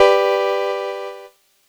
Cheese Chord 01-G2.wav